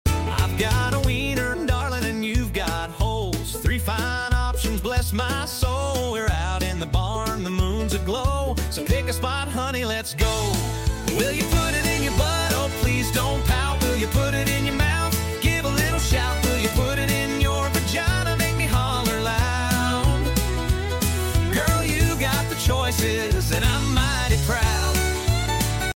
Funny ai generated music.